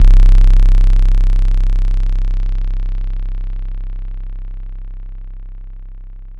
Synth Bass (CHUM).wav